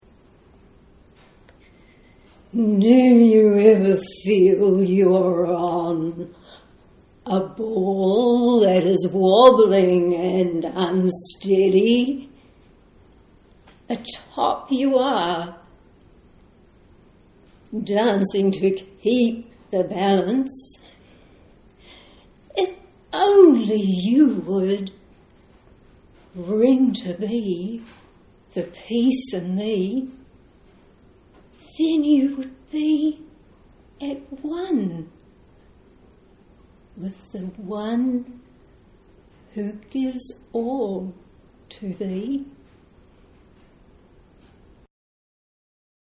Bear in mind that it is a live recording and please excuse the deficiencies in sound quality.